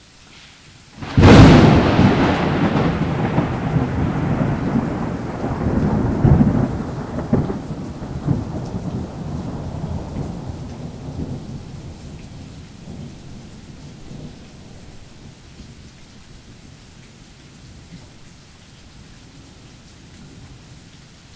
doors.wav